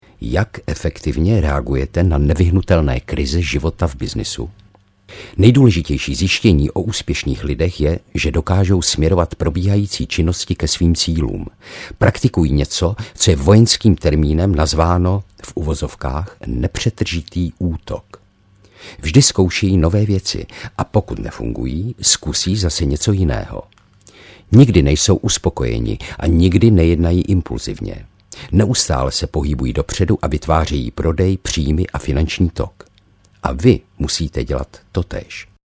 Jak vydělávat v době recese audiokniha
Ukázka z knihy